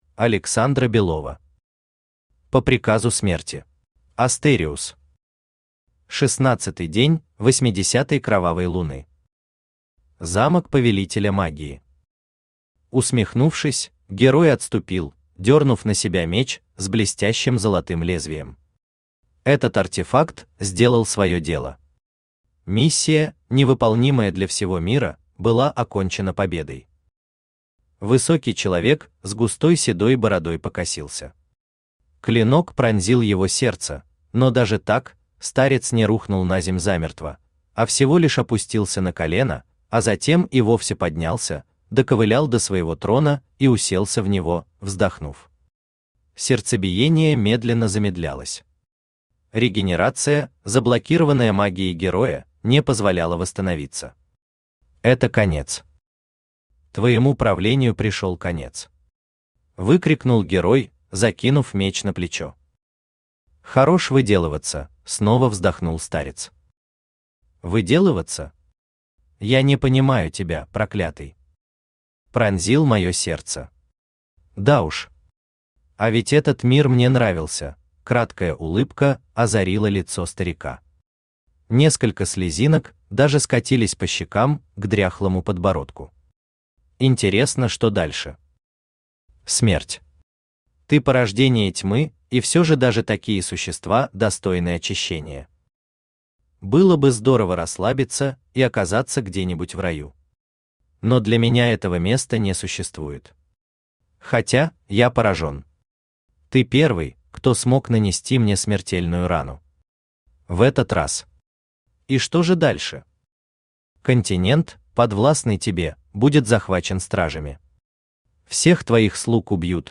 Аудиокнига По приказу смерти | Библиотека аудиокниг
Aудиокнига По приказу смерти Автор Александра Белова Читает аудиокнигу Авточтец ЛитРес.